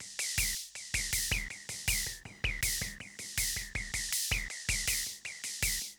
Ambient
DRUMLOOP047_AMBNT_160_X_SC3(L).wav
1 channel